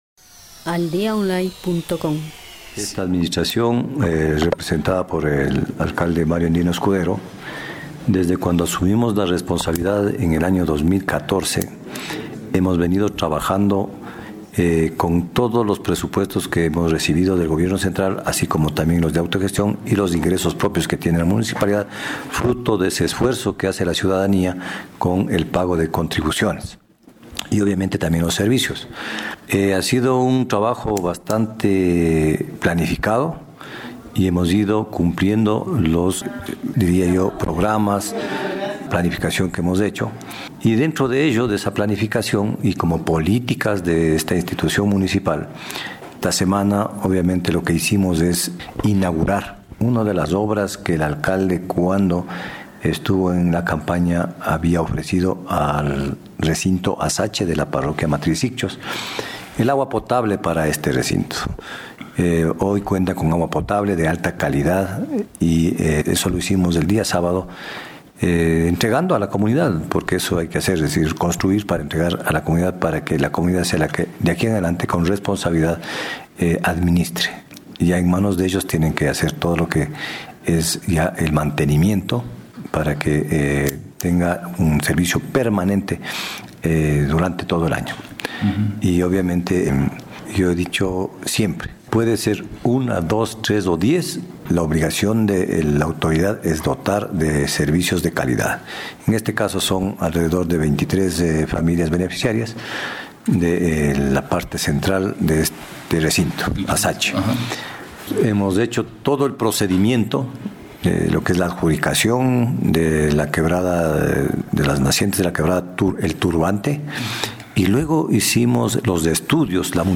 AUDIO: DR. MARIO ANDINO ESCUDERO-ALCALDE DEL GAD. SIGCHOS